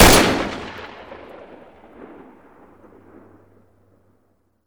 svd_distance_fire1.wav